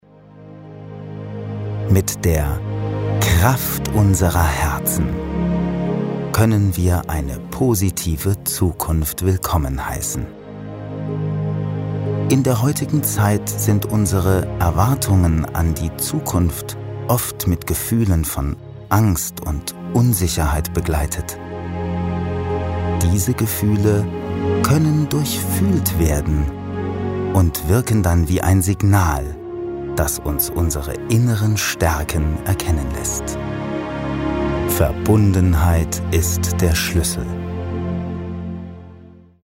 Sprecher Imagefilm, Dokumentarfilm, Off Sprecher, Voice over, Werbesprecher, Feature, Hörbuch, Hörspiel, Point of Sale, DMAX, Vodafone, Synchronsprecher, Sonore Stimme, Arte, 3SAT
Sprechprobe: Werbung (Muttersprache):